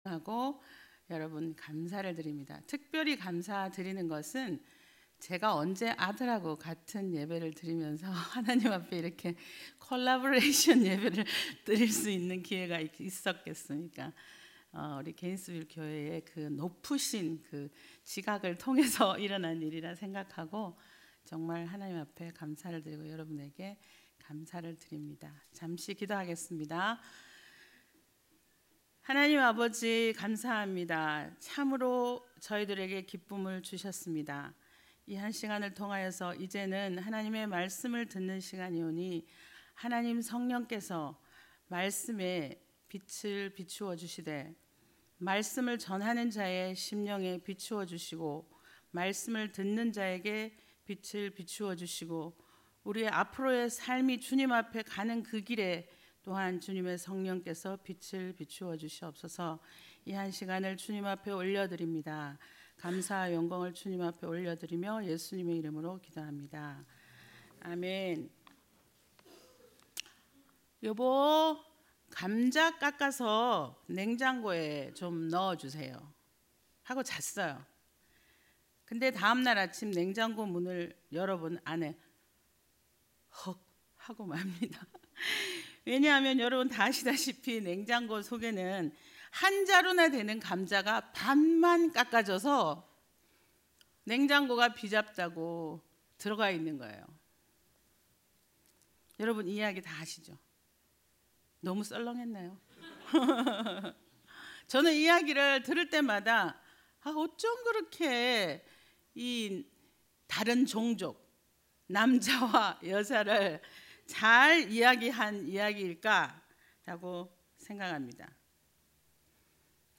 관련 Tagged with 주일예배 Audio (MP3) 64 MB 이전 가정 : 삶의 가치가 풍성히 피어나야 할 곳 다음 야고보서 (20) - 인내하는 자를 복되다 하나니 0 댓글 댓글 추가 취소 댓글을 달기 위해서는 로그인 해야합니다.